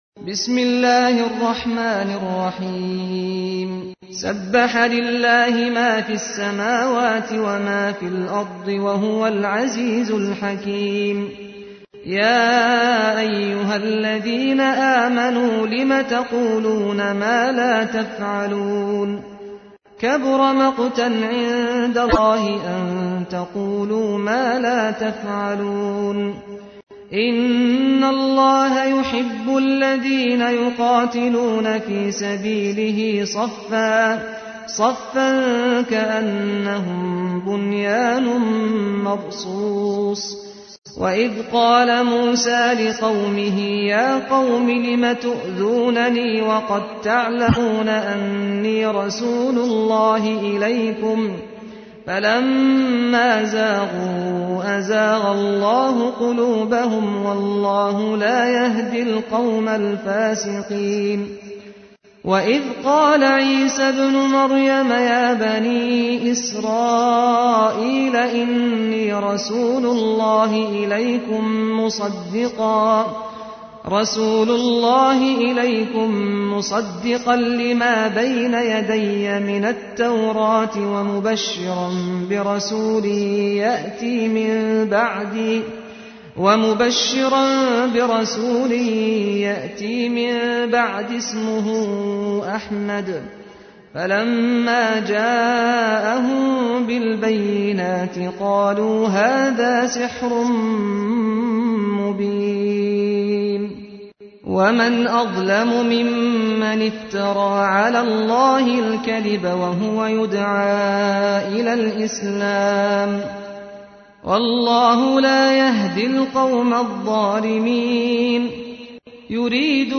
ترتیل سوره صف با صدای استاد سعد الغامدی